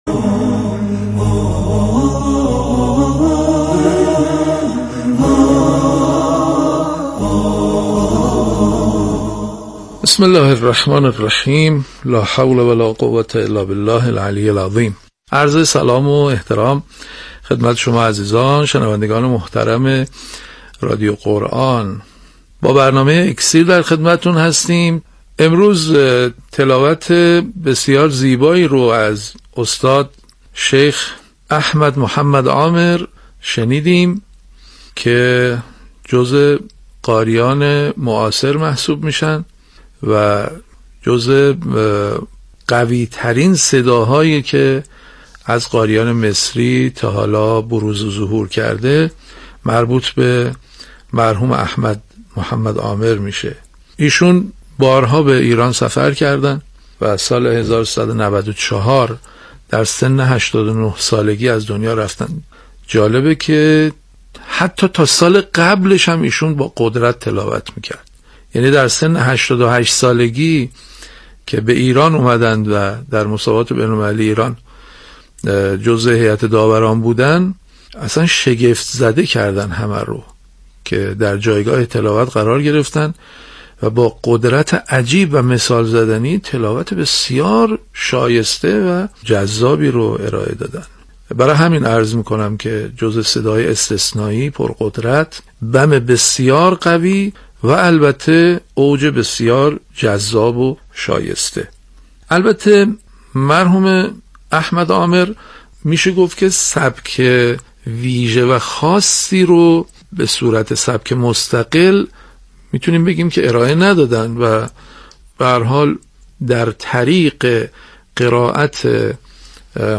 تلاوت جذاب و قدرتمند قاری 88‌ ساله
یادآور می‌شود، این تحلیل در برنامه «اکسیر» از شبکه رادیویی قرآن پخش شد.